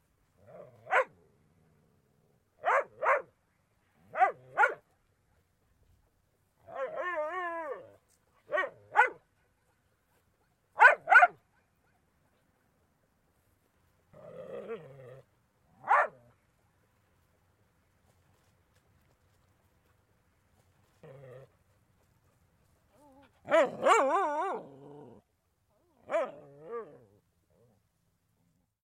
dog-sound